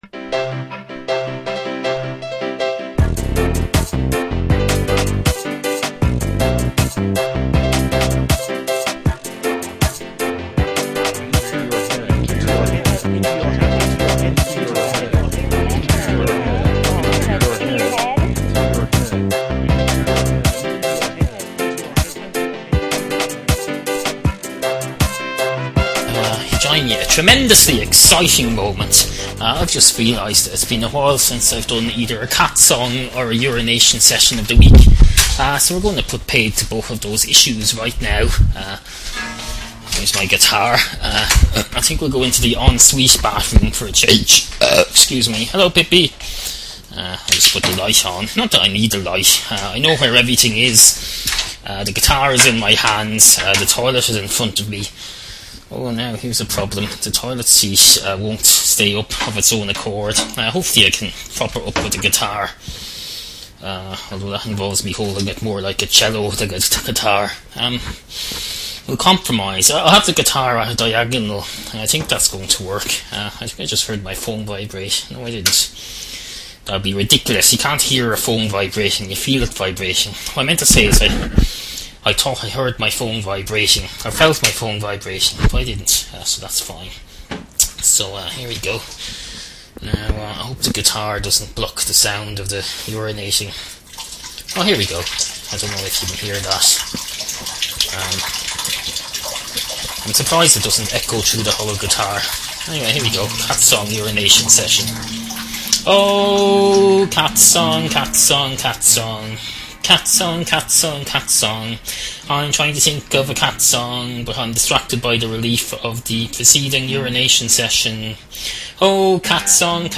Obscure 21st Century Irish audio comedy series
Also: Some sort of a cat song that involves two bathrooms, for some reason.